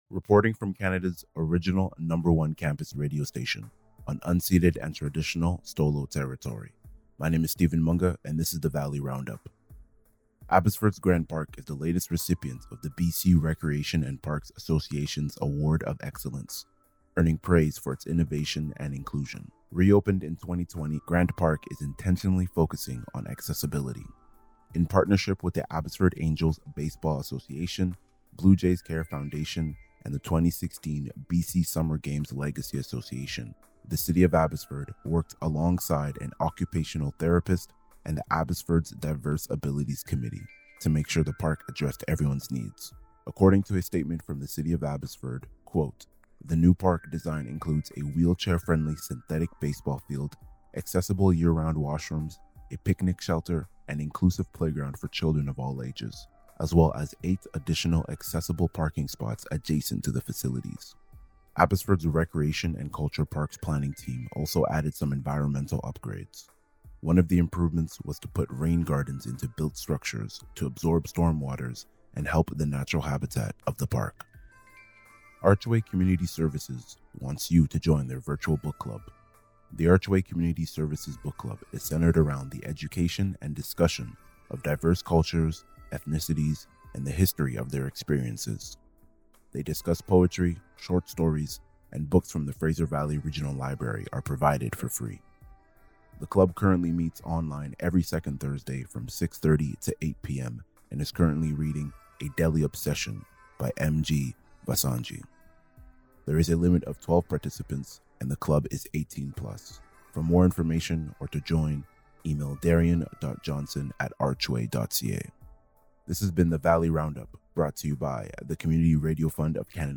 Reporting from Canada’s original #1 campus radio station, on unceded and traditional Stó:lō territory.